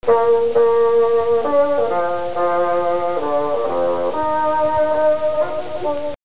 bassoon.mp3